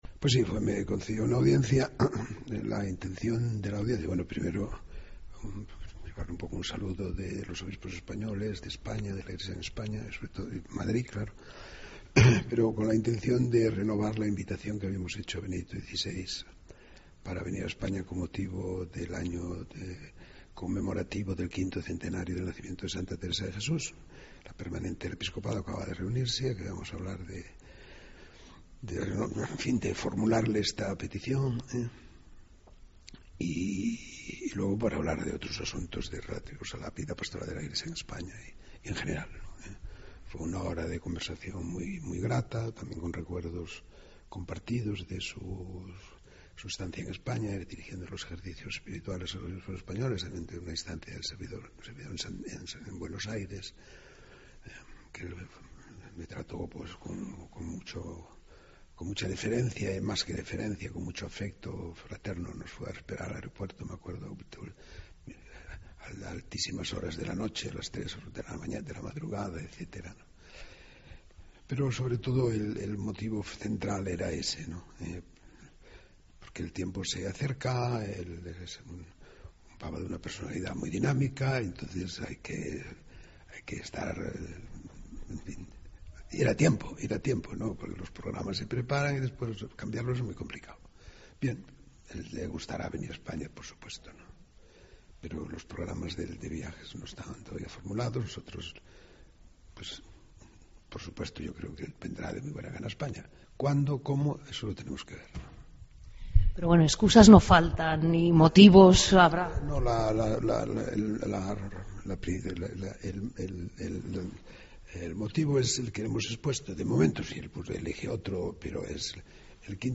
Escucha aquí la entrevista a Monseñor Rouco Varela